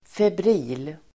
Ladda ner uttalet
febril.mp3